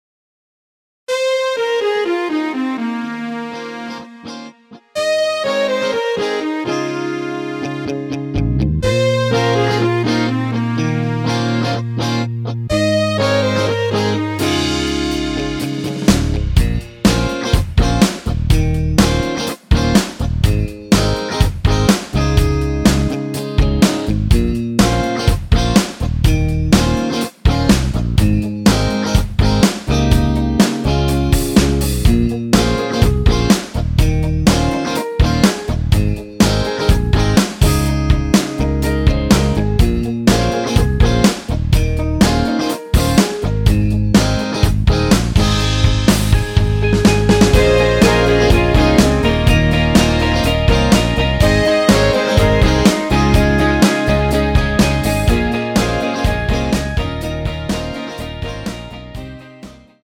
원키에서(+4)올린 MR입니다.
여성분이 부르실수 있는 키의 MR입니다.
Ab
앞부분30초, 뒷부분30초씩 편집해서 올려 드리고 있습니다.
중간에 음이 끈어지고 다시 나오는 이유는